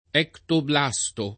ectoblasto [ H ktobl #S to ] s. m. (biol.)